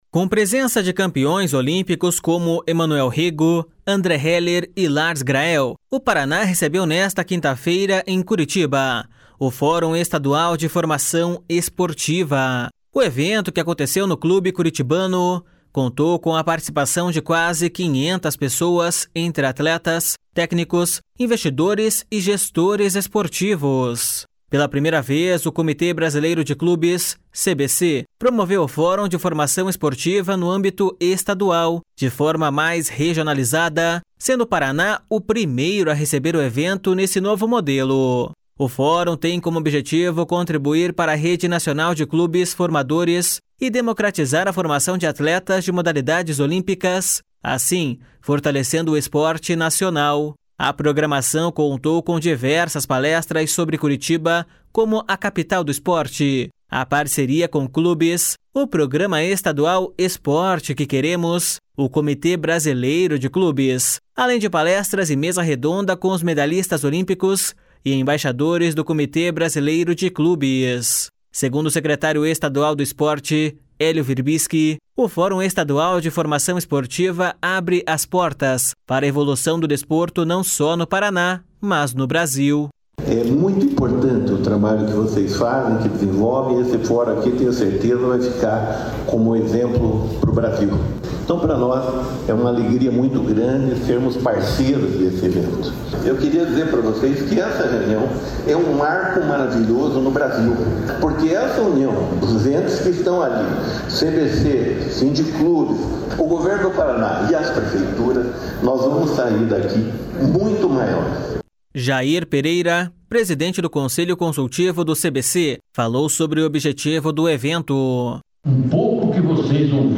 Segundo o secretário estadual do Esporte, Helio Wirbiski, o Fórum Estadual de Formação Esportiva abre portas para a evolução do desporto não só no Paraná, mas no Brasil.// SONORA HELIO WIRBISKI.//
Para o medalhista olímpico e embaixador do Comitê Brasileiro de Clubes, Emanuel Rego, o evento é importante, pois permite o encontro de pessoas e ideias para discutir questões relevantes ao esporte de formação.// SONORA EMANUEL REGO.//